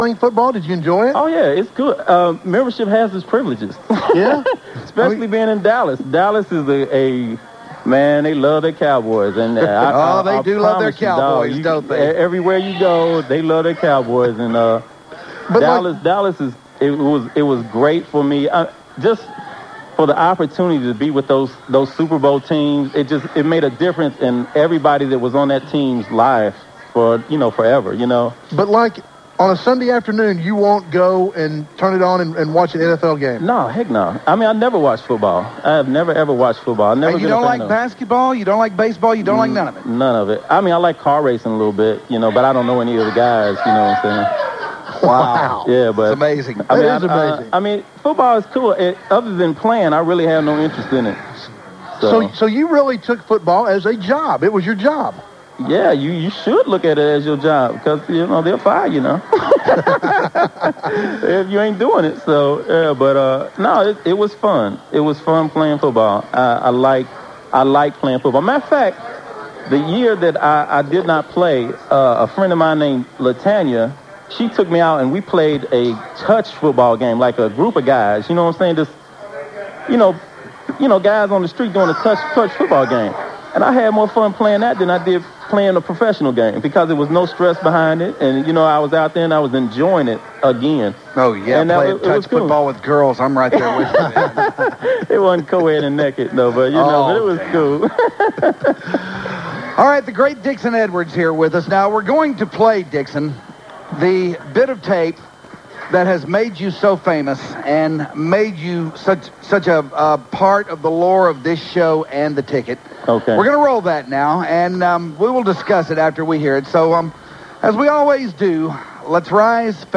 After he retired, Dixon Edwards stopped by and visited The Hardline and after a minute and a half or so of them acting like they cared about his career and life after Dallas, they got into the subject of his infamous string of “You Know”s that he had unleased on them in a phone interview they had with him on the day after he left Dallas to sign with the Vikings. The clip is played a few times in the interview, so lets all rise and face the north and pay heed once again… to Dixon Edwards, You Know.